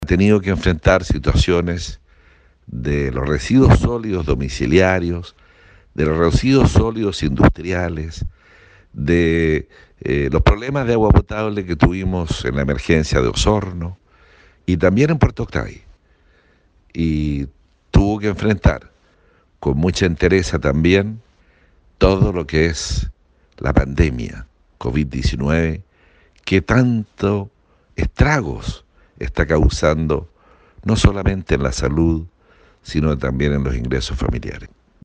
Mientras estuvo a cargo de la cartera de salud debió enfrentar complejos episodios que afectaron la salud de la población, según recordó el jefe regional.